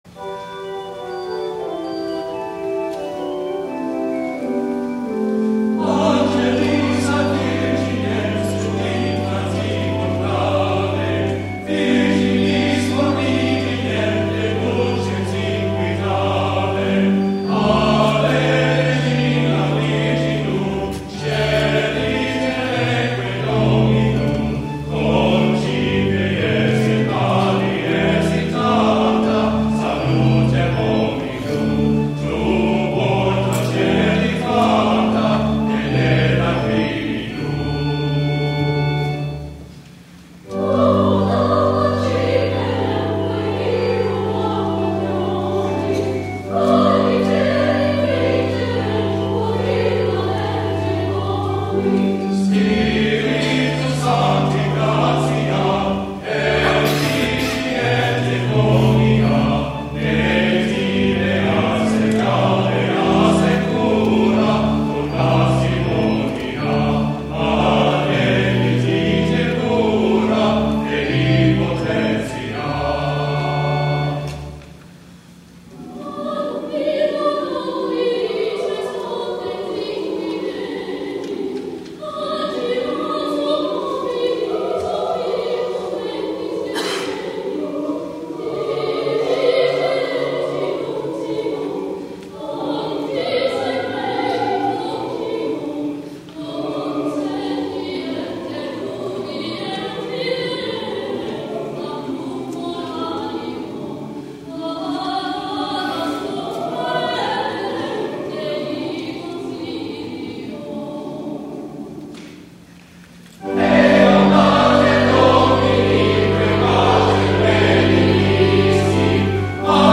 THE ANTHEM
sung in Latin